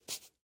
Minecraft Version Minecraft Version snapshot Latest Release | Latest Snapshot snapshot / assets / minecraft / sounds / mob / armadillo / ambient5.ogg Compare With Compare With Latest Release | Latest Snapshot